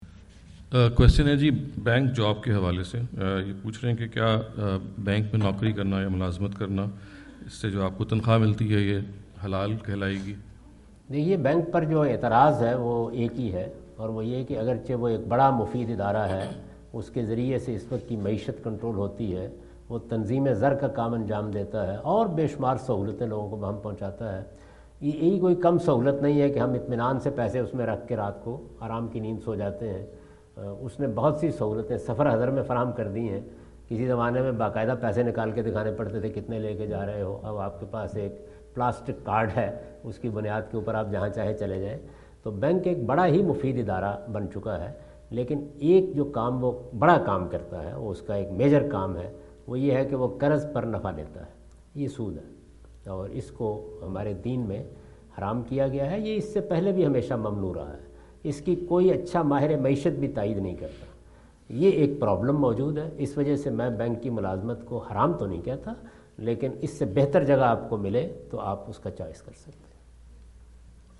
Javed Ahmad Ghamidi answer the question about "Is Income of Bank Employee’s Haram or Halal?" During his US visit in Dallas on October 08,2017.
جاوید احمد غامدی اپنے دورہ امریکہ2017 کے دوران ڈیلس میں "کیا بینک ملازمین کی تنخواہ حلال ہے یا حرام؟" سے متعلق ایک سوال کا جواب دے رہے ہیں۔